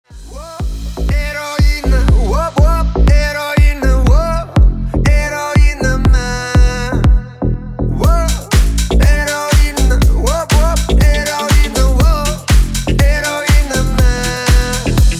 deep house
душевные
dance
nu disco
Chill House